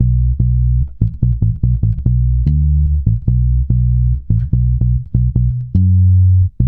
-MM RAGGA B.wav